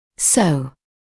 [səu][соу]так, таким образом; итак